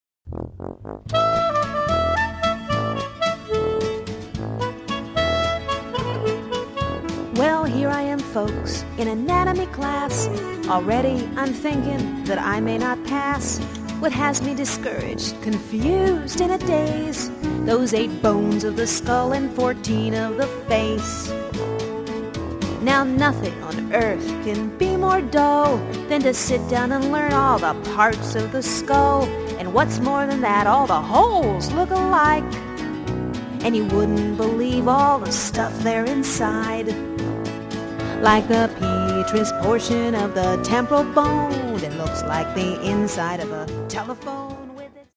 --humorous acoustic music